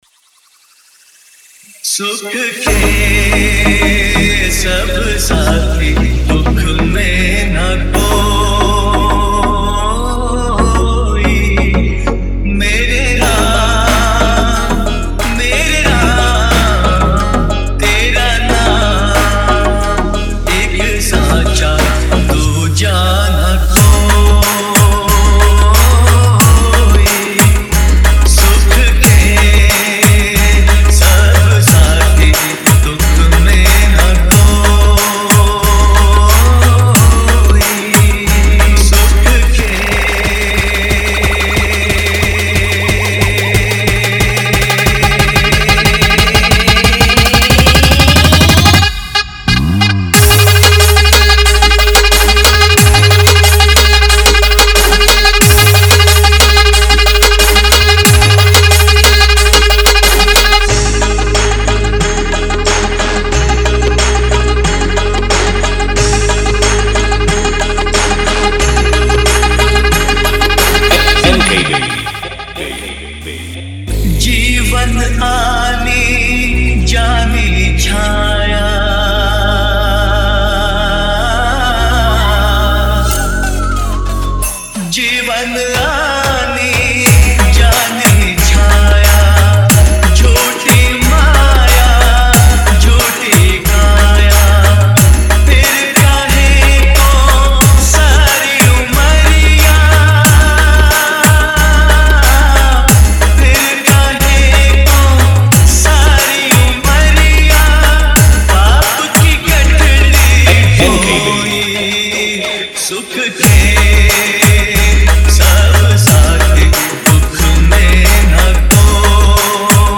Desi Trap Mix